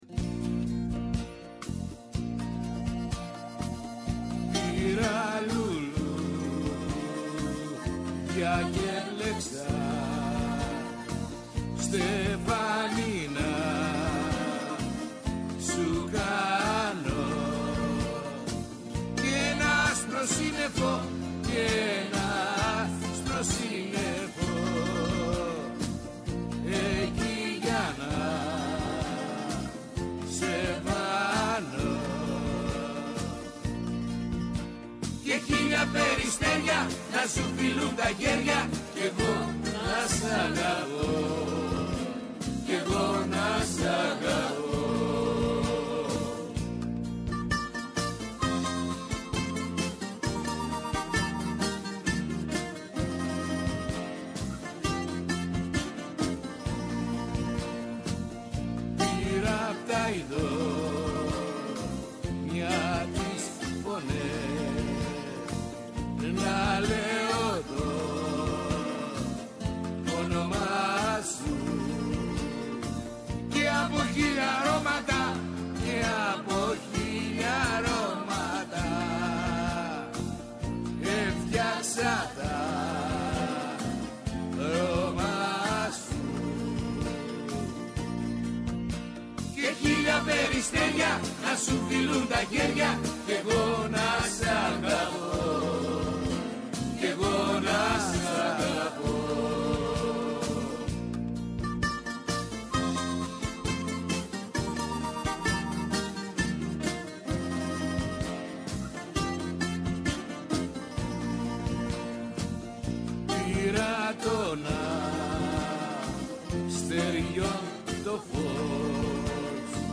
Στο Doc On Air απόψε, λίγο μετά τις 8 το βράδυ, υποδεχόμαστε στo στούντιο